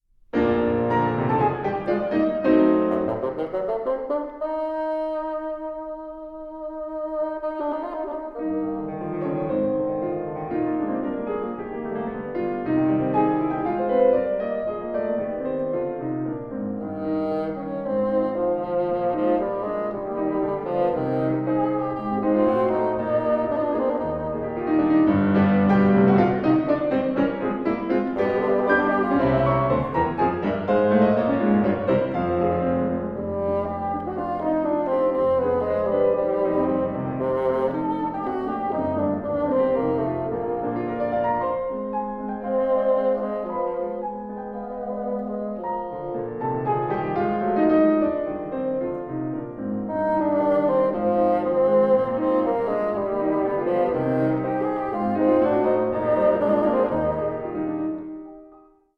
Sonatas and Nocturnes - 19th Century Gems for Bassoon and Piano
Stereo
bassoon
piano
Recorded 16 and 17 January 2017 in the Concert Hall of the Conservatoire de musique de Montréal